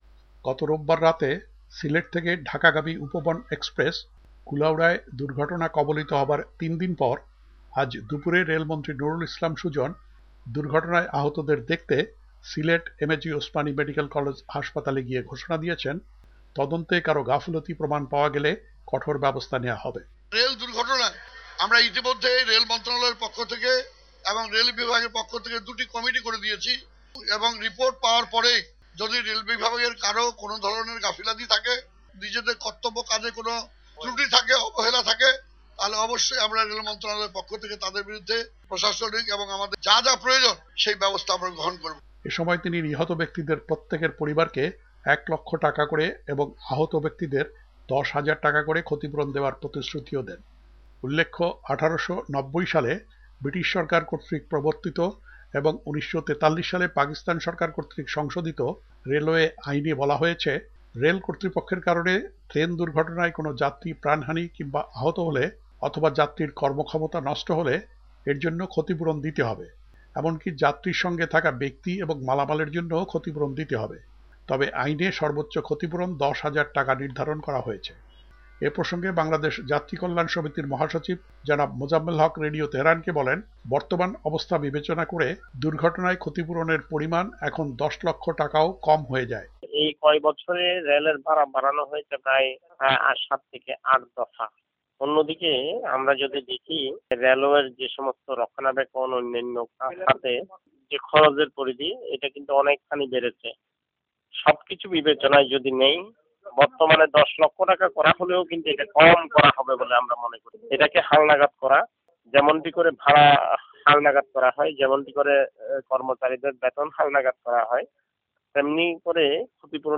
ট্রেন দুর্ঘটনায় মৃত ব্যক্তির পরিবার পাবে ১ লাখ টাকা: প্রতিক্রিয়া বিশ্লেষকের